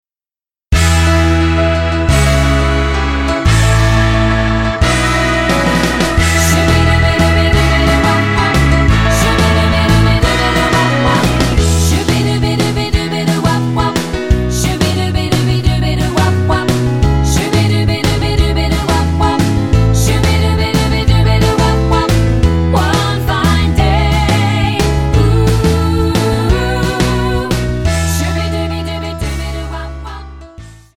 Tonart:E-F-G# mit Chor
Die besten Playbacks Instrumentals und Karaoke Versionen .